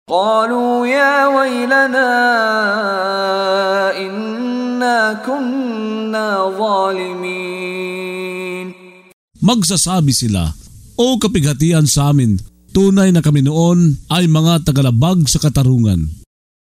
Pagbabasa ng audio sa Filipino (Tagalog) ng mga kahulugan ng Surah Al-Anbiyấ ( Ang Mga Propeta ) na hinati sa mga taludtod, na sinasabayan ng pagbigkas ng reciter na si Mishari bin Rashid Al-Afasy. Ang pagtitibay sa mensahe, ang paglilinaw sa kaisahanng layon ng mga propeta, at ang pangangalaga ni Allāhsa kanila